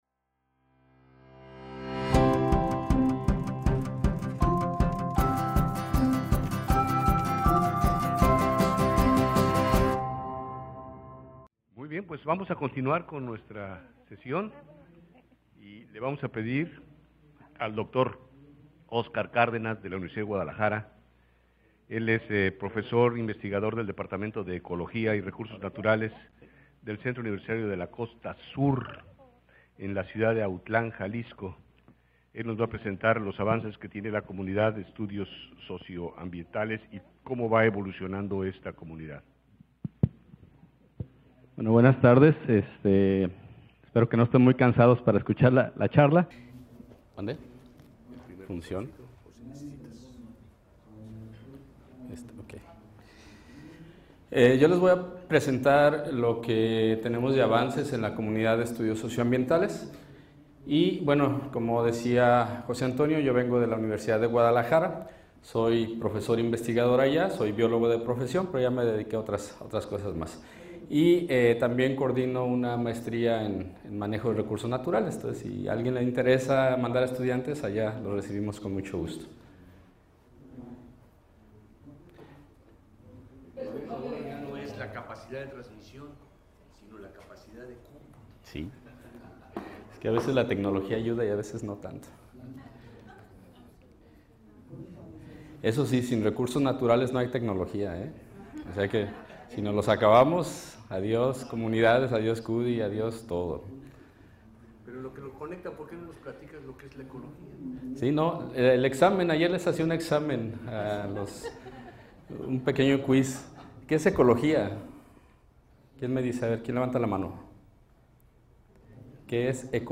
Audio de las conferencias